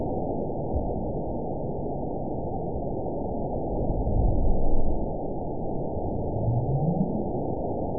event 912505 date 03/27/22 time 23:49:55 GMT (3 years, 1 month ago) score 9.59 location TSS-AB04 detected by nrw target species NRW annotations +NRW Spectrogram: Frequency (kHz) vs. Time (s) audio not available .wav